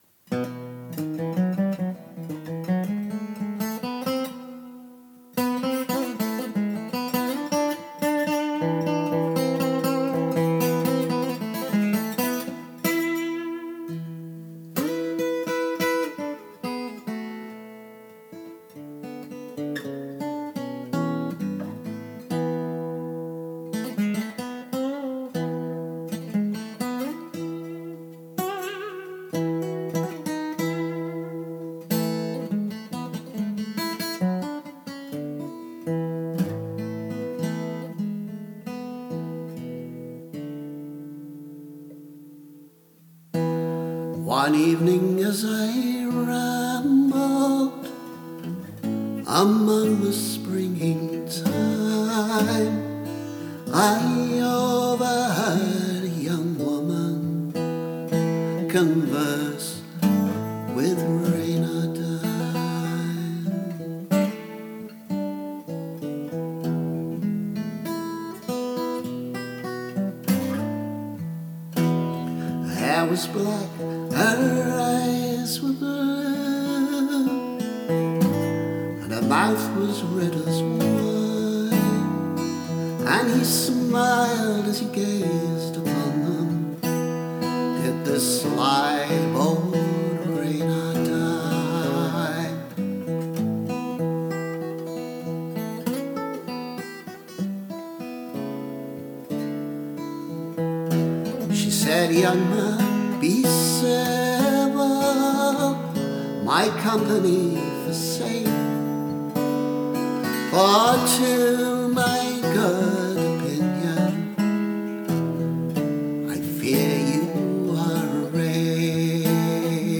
A very 60s-ish guitar arrangement of a traditional song.